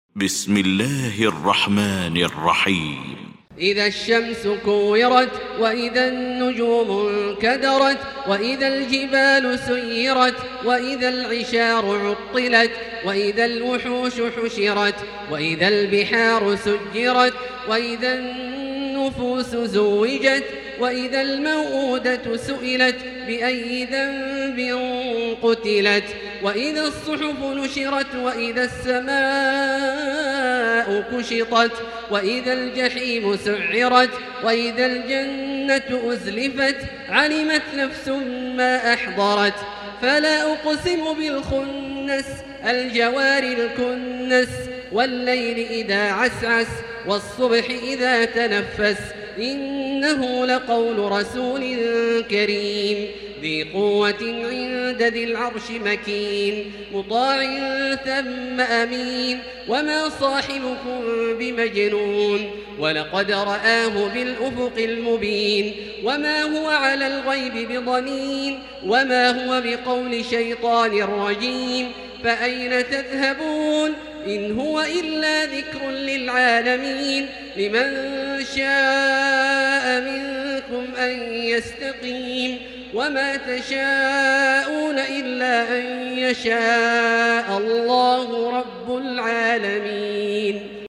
المكان: المسجد الحرام الشيخ: فضيلة الشيخ عبدالله الجهني فضيلة الشيخ عبدالله الجهني التكوير